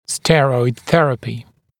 [‘sterɔɪd ‘θerəpɪ][‘стэройд ‘сэрэпи]стероидная терапия